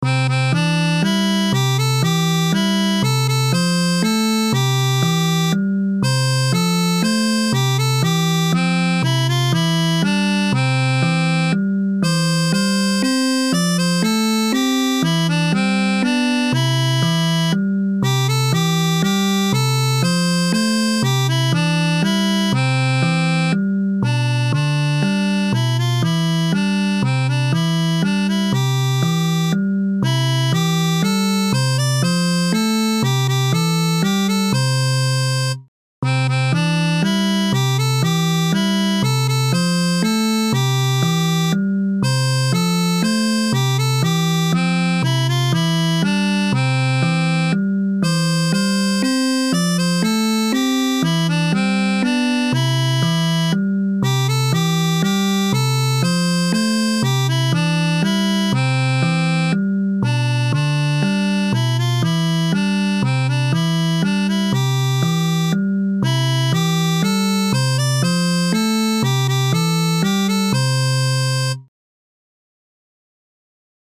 童謡・唱歌：『背くらべ』